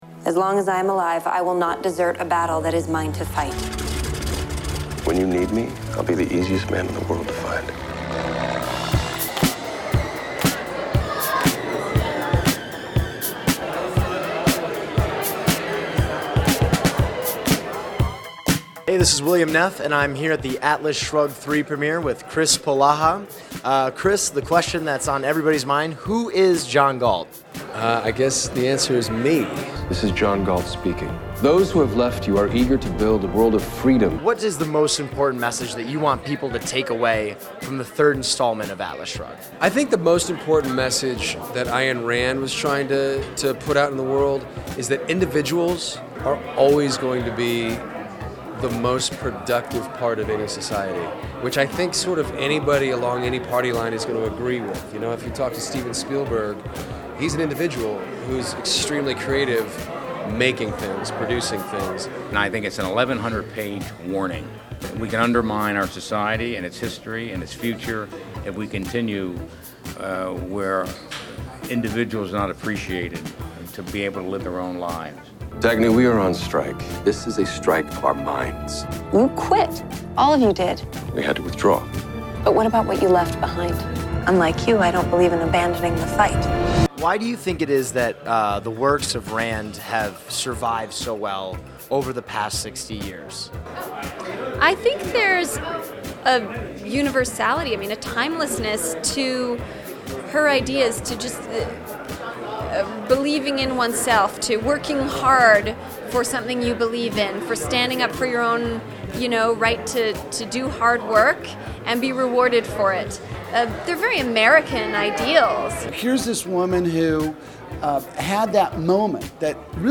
Atlas Shrugged Part III premiered at the Palms Casino Resort in Las Vegas, Nevada, on September 7, 2014.
Reason TV was on hand to ask the actors, producers, and fans what it was like to make the movie and why they think Ayn Rand's work remains appealing decades after its publication.